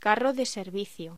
Locución: Carro de servicio
voz